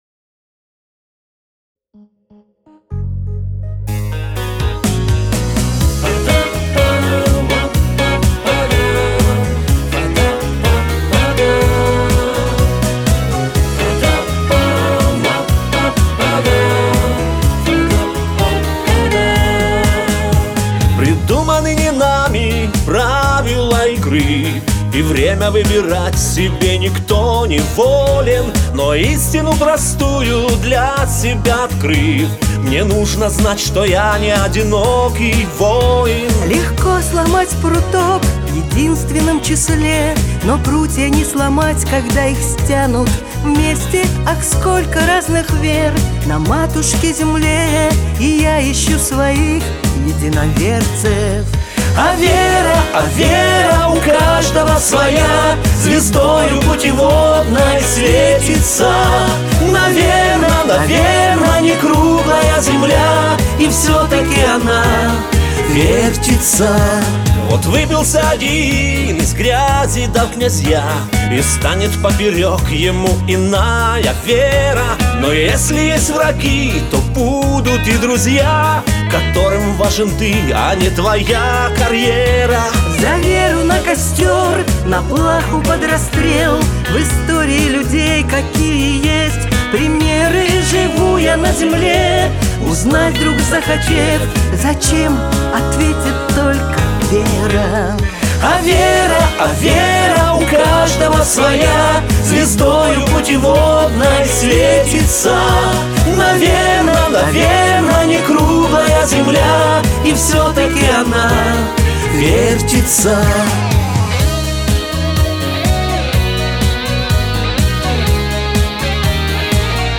бэк-вокал